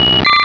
Cri de Poissirène dans Pokémon Rubis et Saphir.